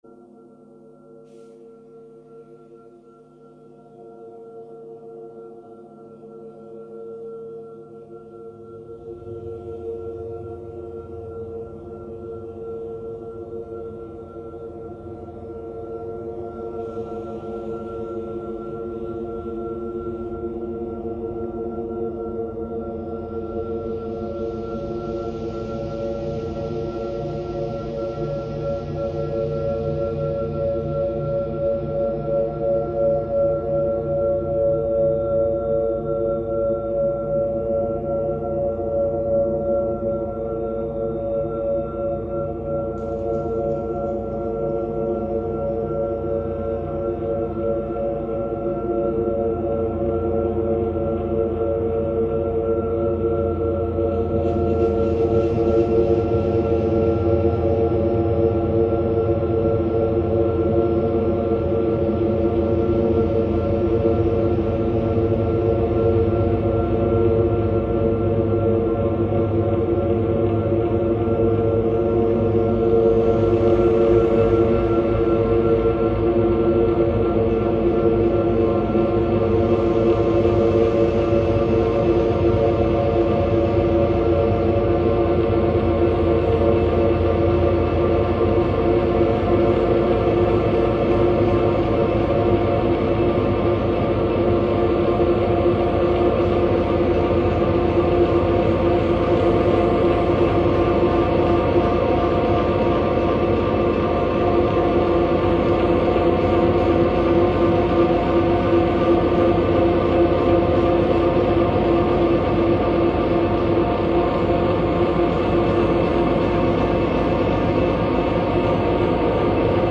File under: Ambient / Experimental
meditative / relaxing ambient music